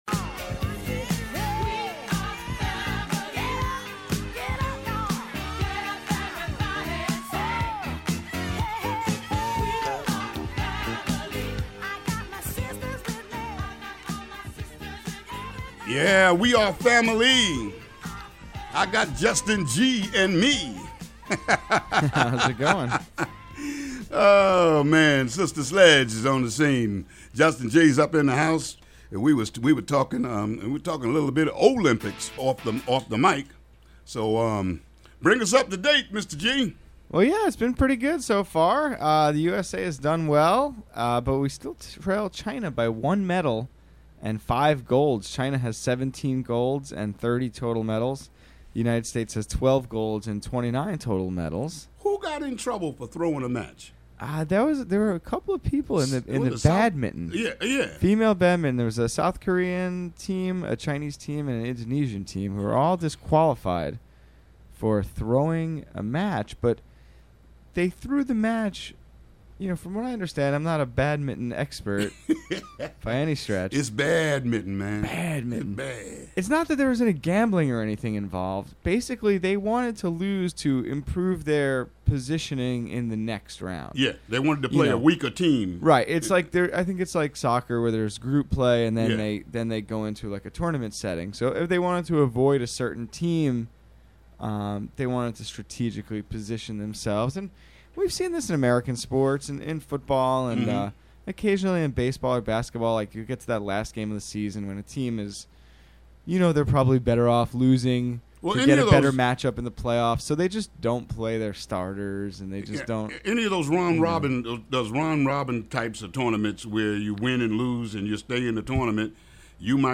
WGXC Morning Show
WGXC Morning Show Contributions from many WGXC programmers.